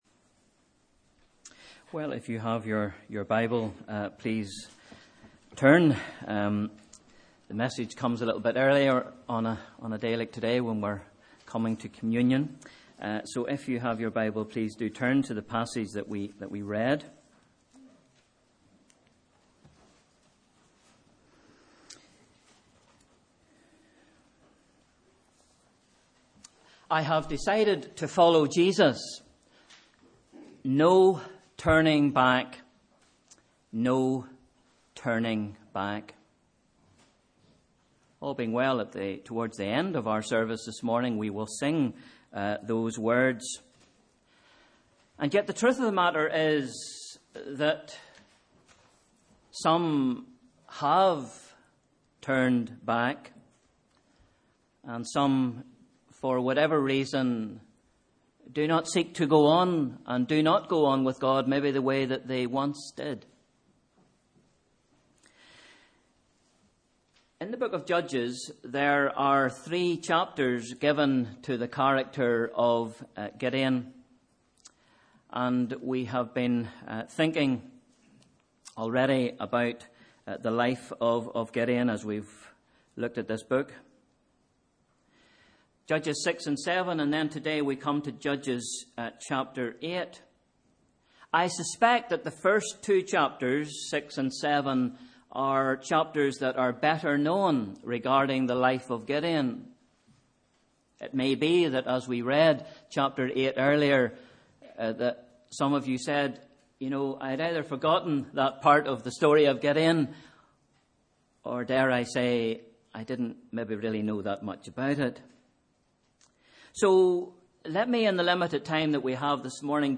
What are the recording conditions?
Sunday 20th May 2018 – Morning Service